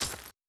Impact on Glass.wav